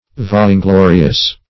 Vainglorious \Vain`glo"ri*ous\, a.